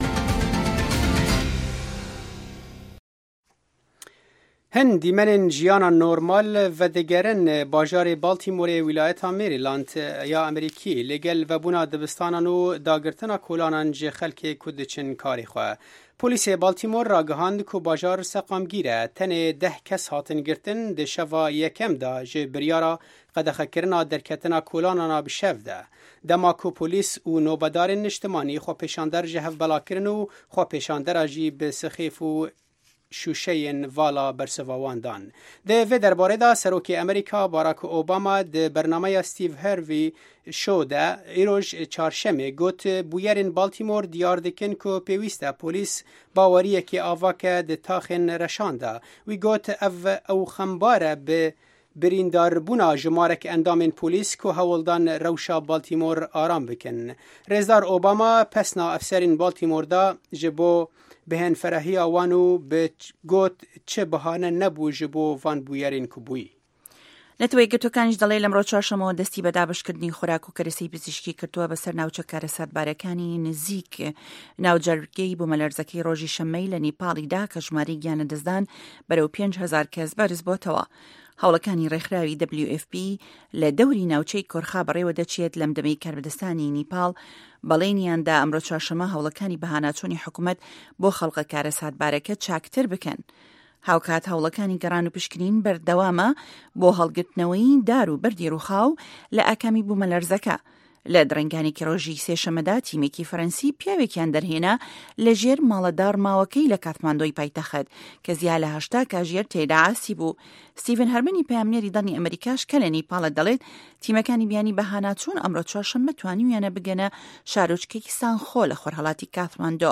هه‌واڵه‌کان، ڕاپـۆرت، وتووێژ، سه‌رگوتاری ڕۆژانه‌‌ که‌ تیایدا ڕاوبۆچوونی حکومه‌تی ئه‌مه‌ریکا ده‌خرێته‌ ڕوو.